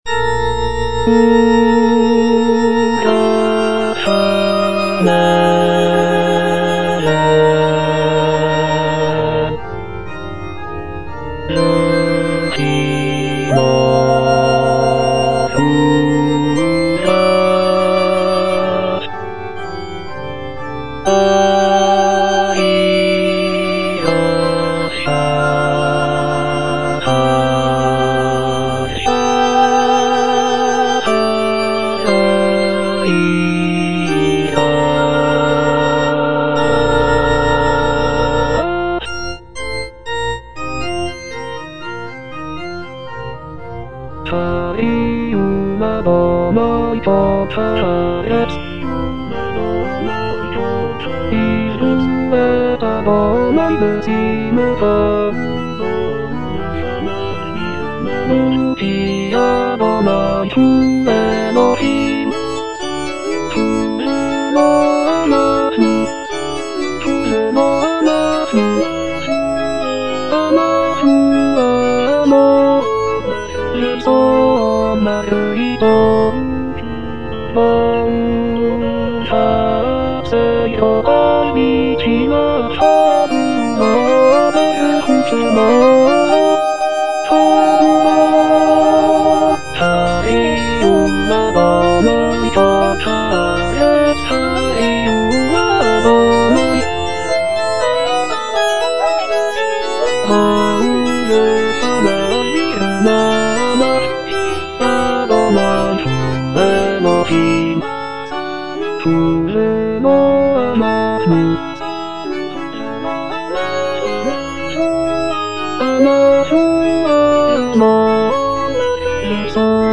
(bass I) (Emphasised voice and other voices) Ads stop